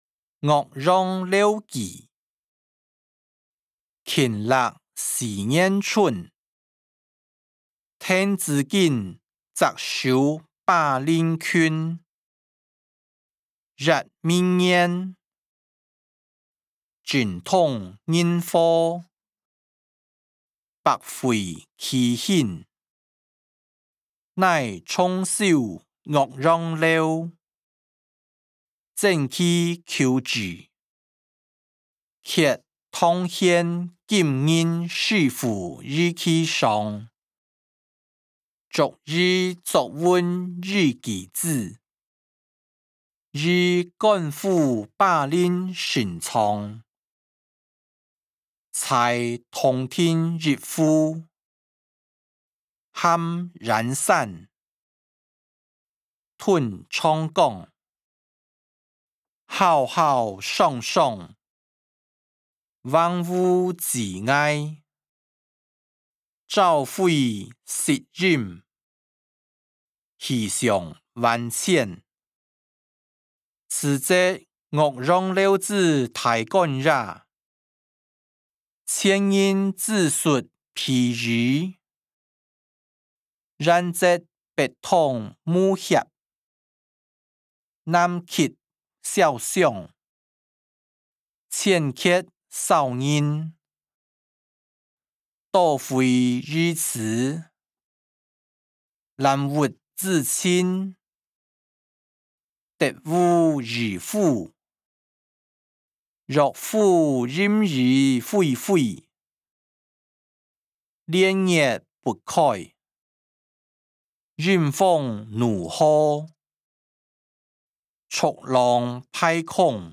歷代散文-岳陽樓記音檔(海陸腔)